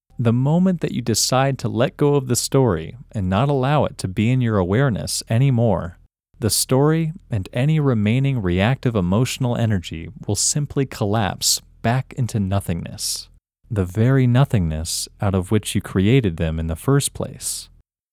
OUT – English Male 28